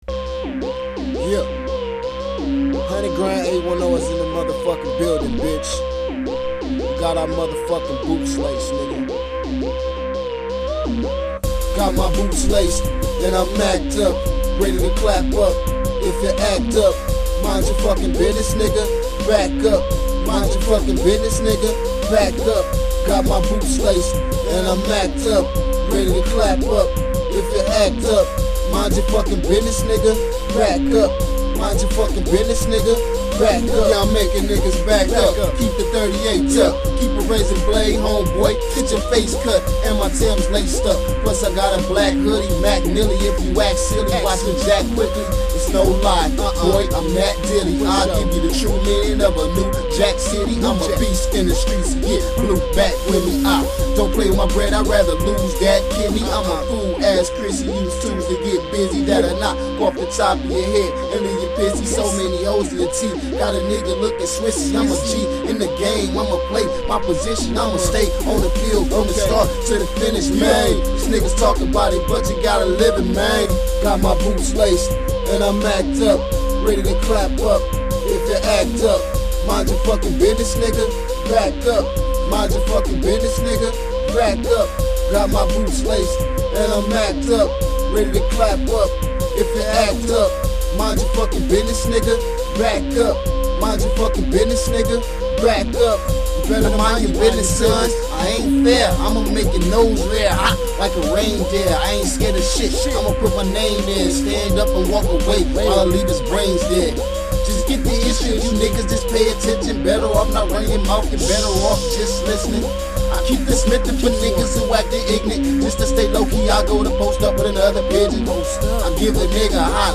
Hardcore
Rap
Hip-hop